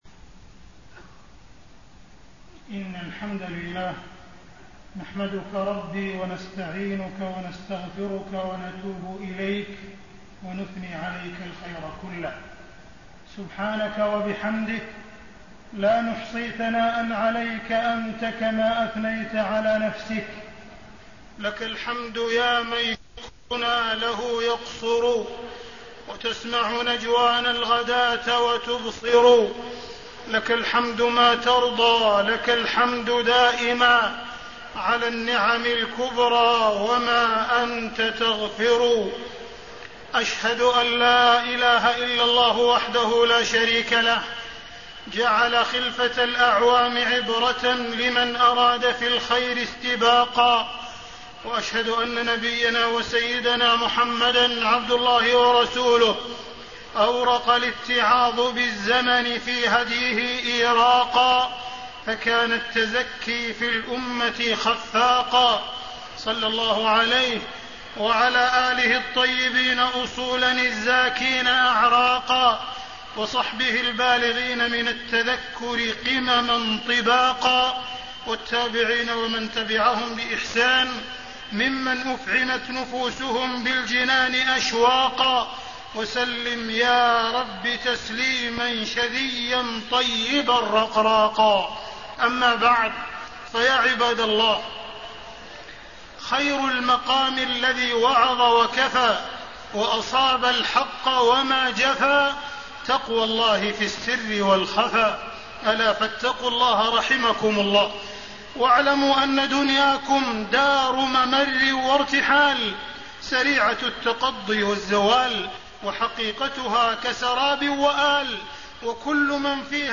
تاريخ النشر ٢٧ ذو الحجة ١٤٣٤ هـ المكان: المسجد الحرام الشيخ: معالي الشيخ أ.د. عبدالرحمن بن عبدالعزيز السديس معالي الشيخ أ.د. عبدالرحمن بن عبدالعزيز السديس عام أفل وعام أهل